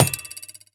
ground_impact3.ogg